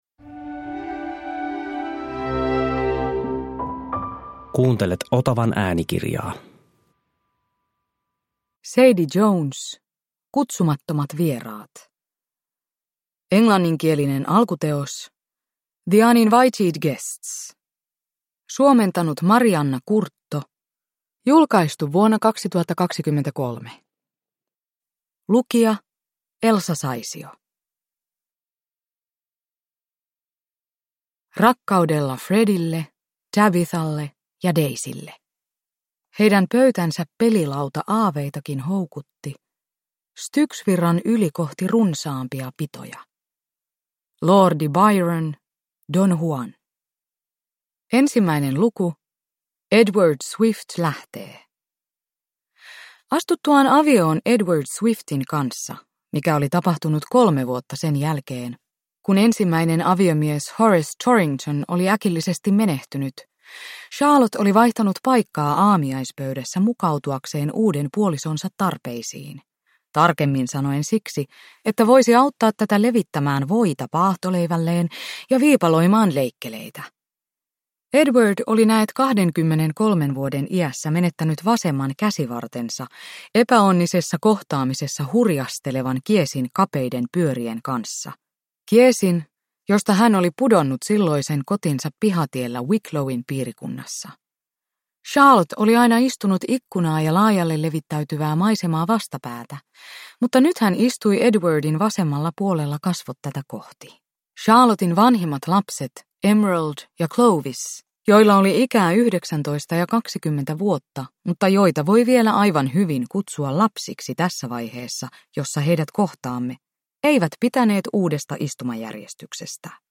Kutsumattomat vieraat – Ljudbok – Laddas ner
Uppläsare: Elsa Saisio